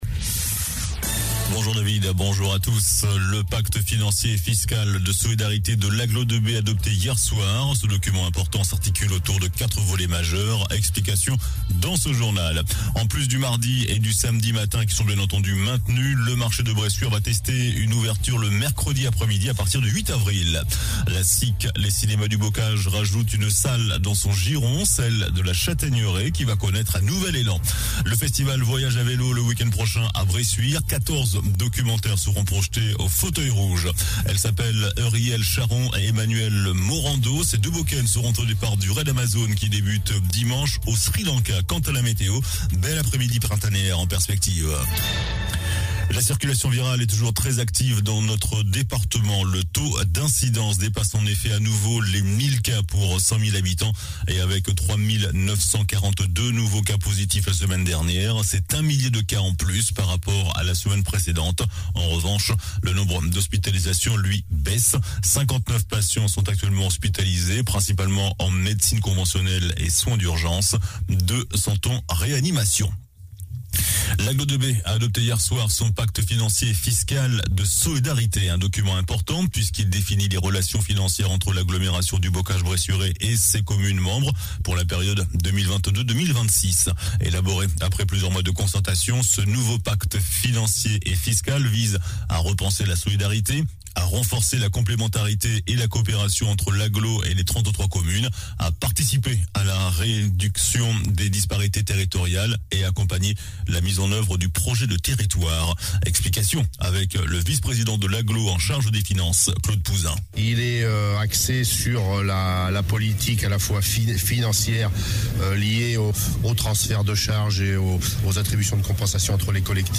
JOURNAL DU MERCREDI 23 MARS ( MIDI )